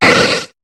Cri de Lilia dans Pokémon HOME.